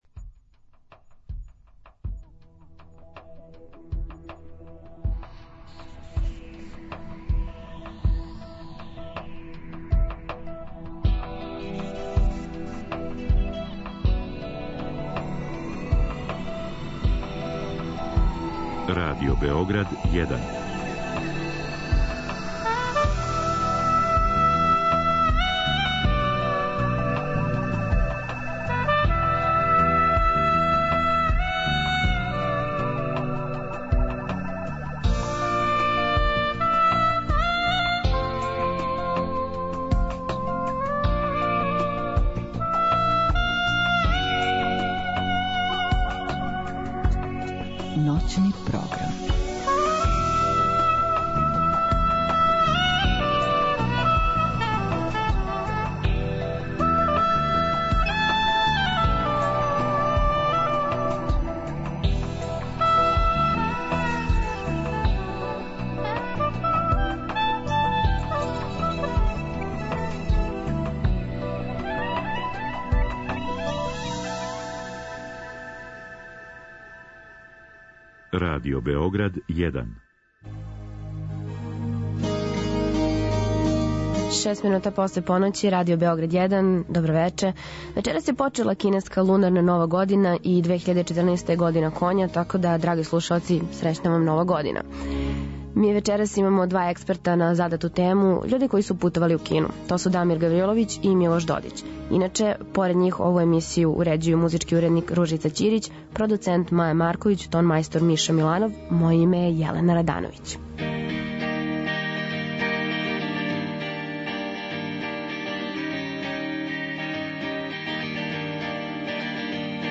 Ноћни програм - Вече радијског хумора